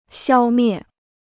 消灭 (xiāo miè): anéantir; exterminer.